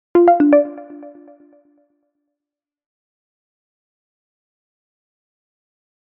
Scifi 6.mp3